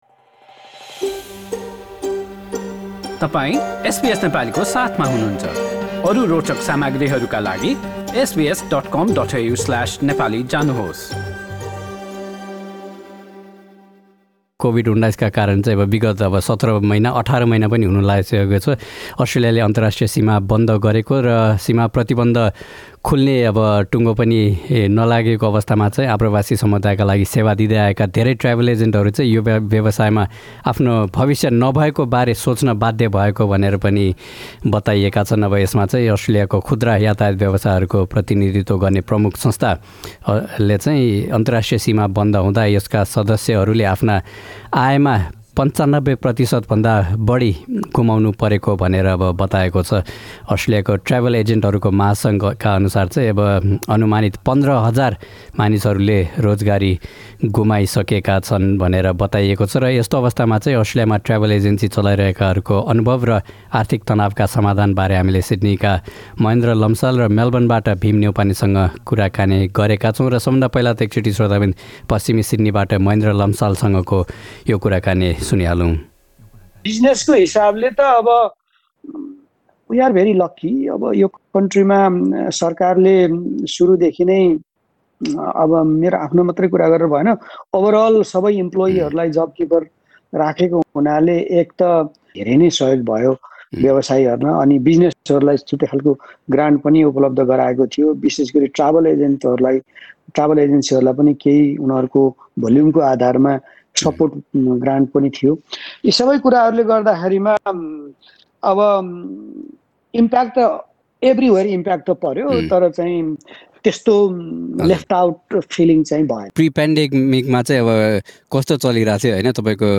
गरेको कुराकानी।